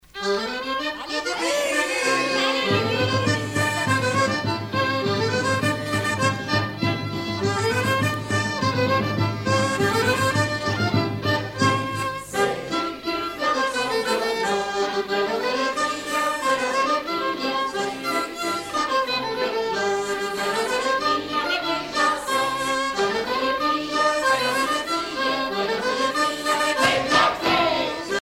Chants brefs - A danser
danse : varsovienne
Pièce musicale éditée